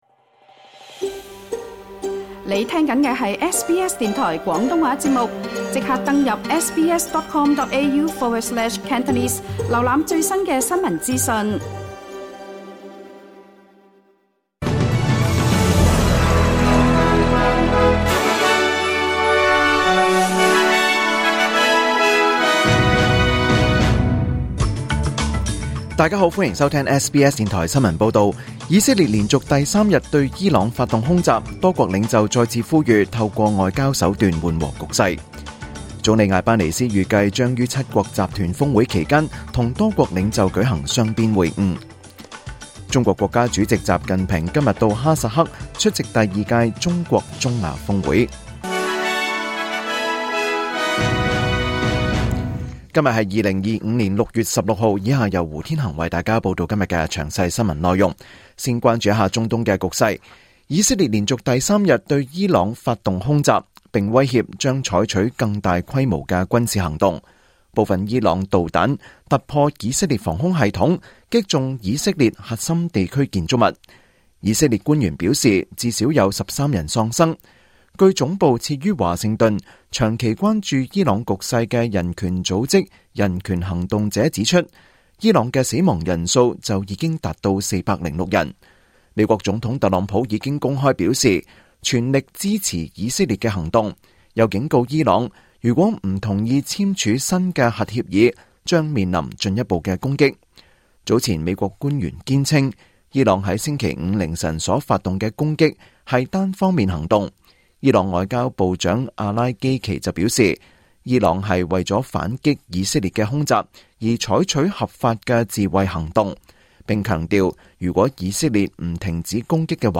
2025年6月16日SBS廣東話節目詳盡早晨新聞報道。